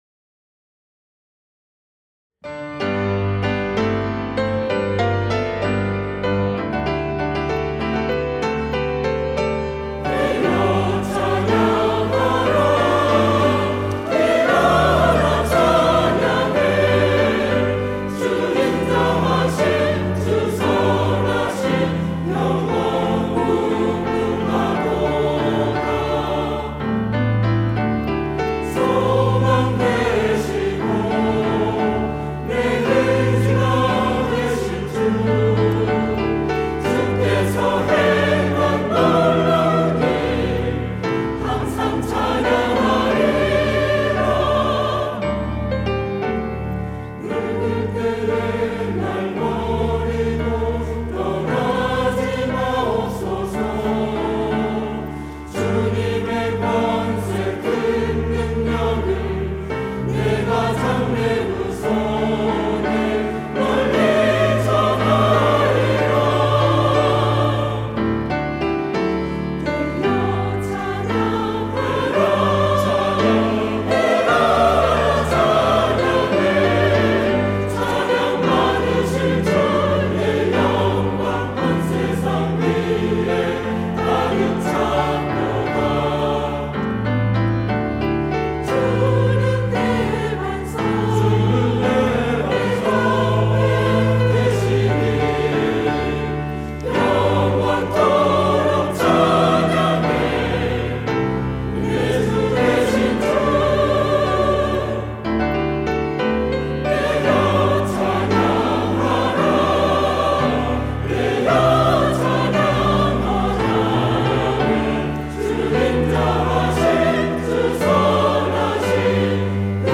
시온(주일1부) - 내 영혼아, 깨어 찬양하라
찬양대